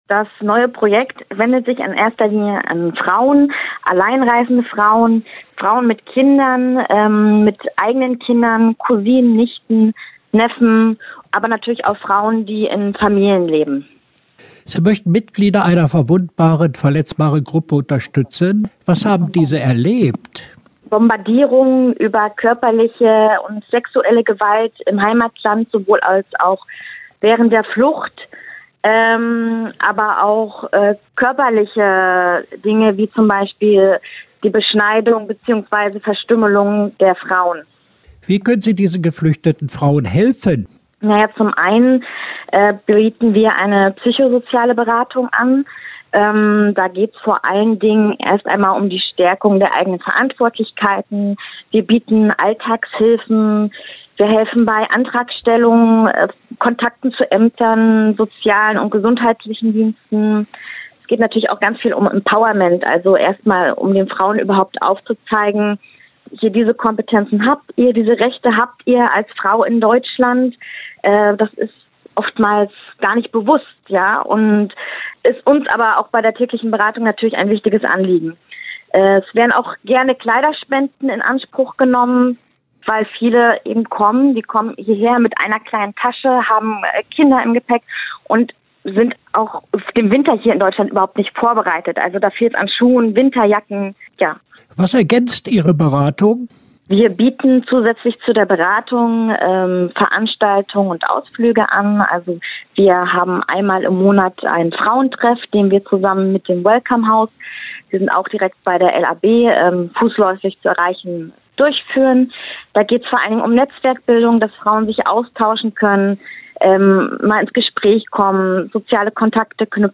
Interview-AWO-Frauenzentrum.mp3